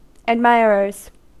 Uttal
Uttal US: IPA : [æd.'maɪ.ə.rə(r)z] UK: IPA : əd.ˈmaɪ.ɹɚz US: IPA : æd.ˈmaɪ.ɚ.ɚz Ordet hittades på dessa språk: engelska Ingen översättning hittades i den valda målspråket.